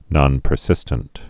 (nŏnpər-sĭstənt)